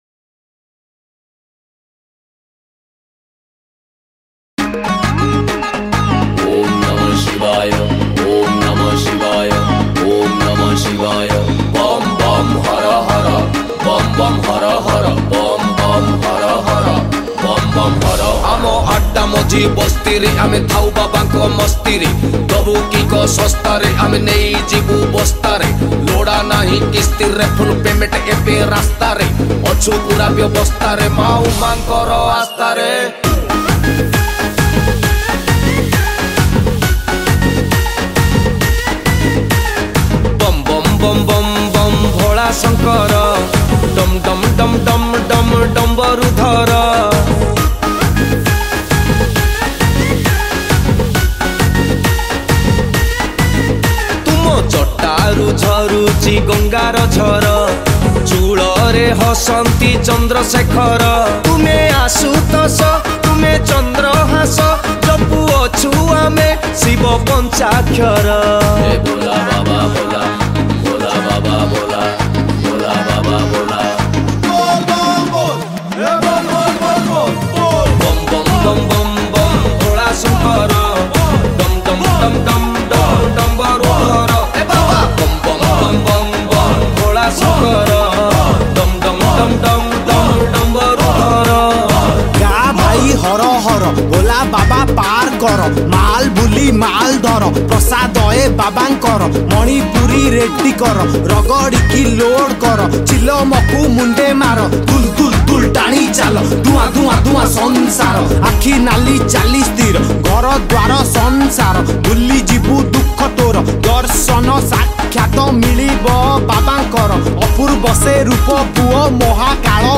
Rapper